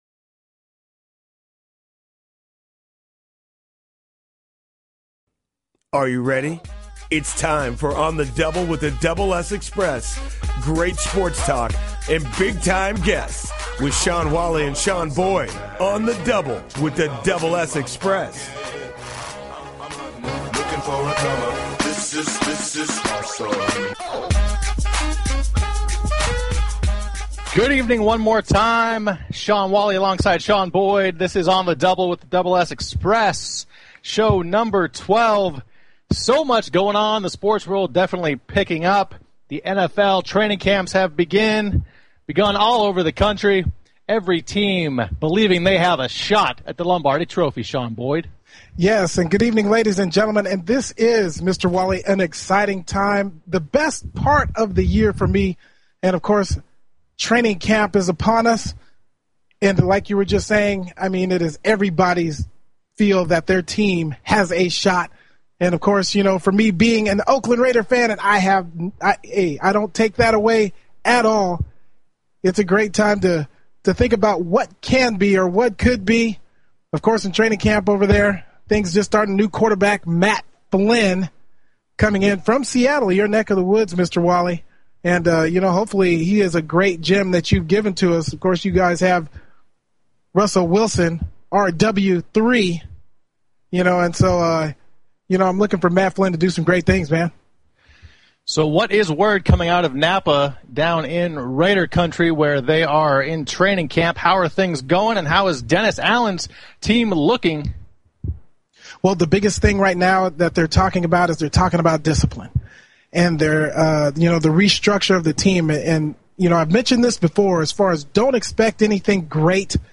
On the Double with The Double S Xpress sports talk show; Sports Broadcasting; Play-by-Play; Analysis; Commentary; Insight; Interviews; Public Address Mission: To provide the best play-by-play & analysis of all sports in the broadcasting world.